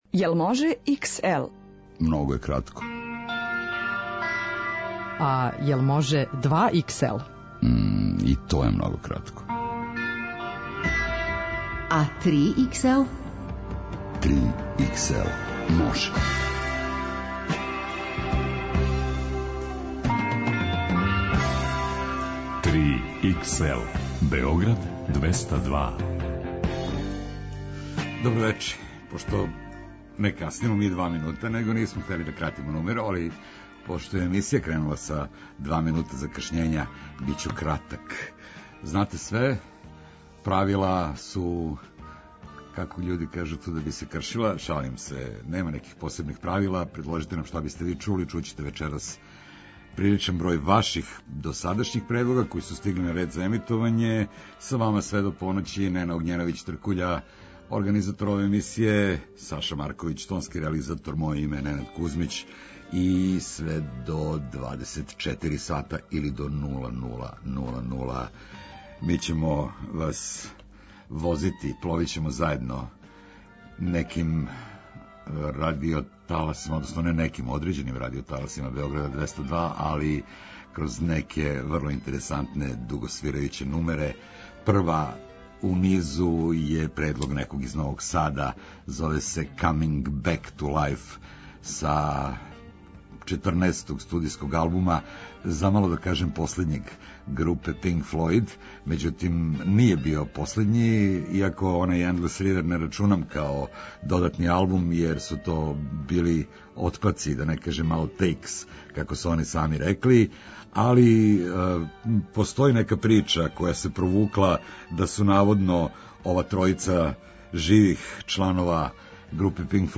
Најдуже музичке нумере.